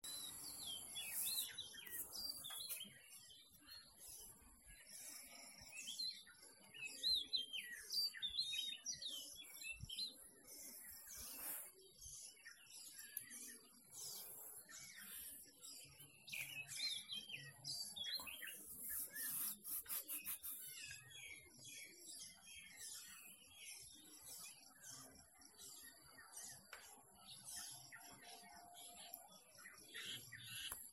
Forest Wild Birds Chirping About 30 seconds